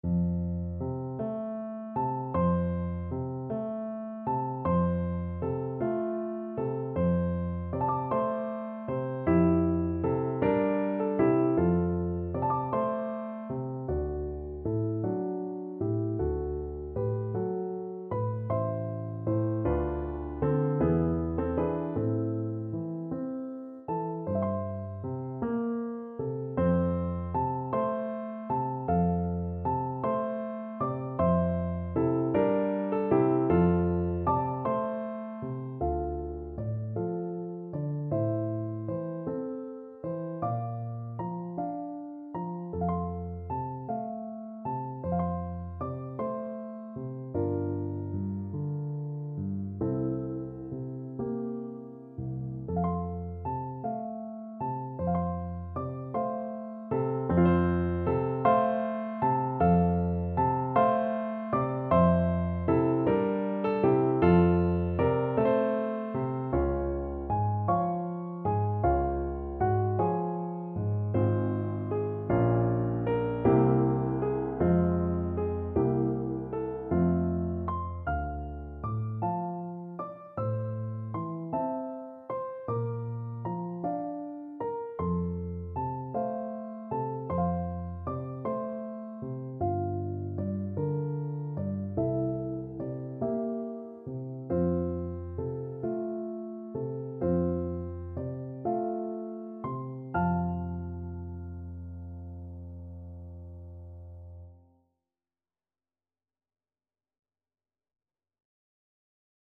~. = 52 Allegretto
6/8 (View more 6/8 Music)
Classical (View more Classical Clarinet Music)